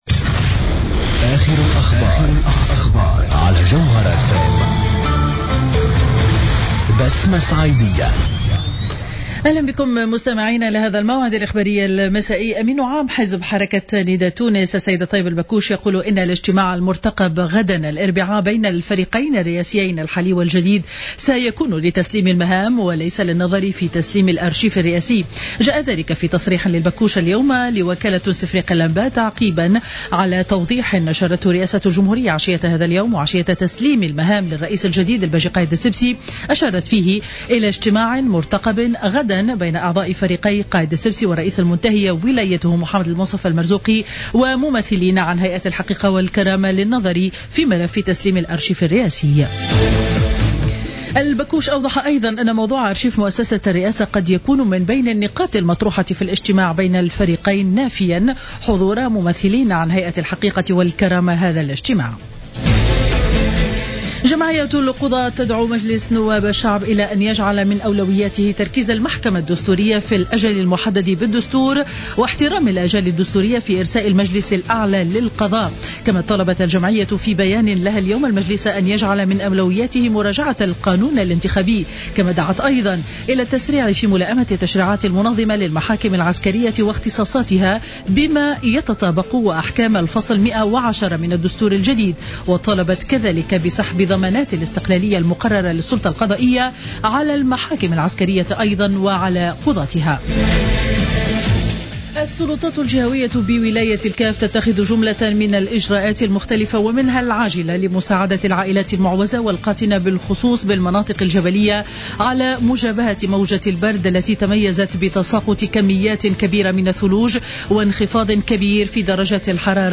نشرة أخبار السابعة مساء ليوم الثلاثاء 30-12-14